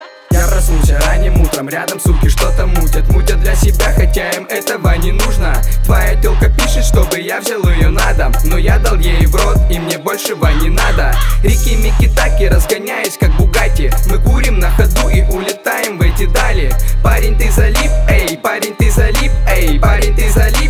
• Качество: 320, Stereo
русский рэп
крутые
грубые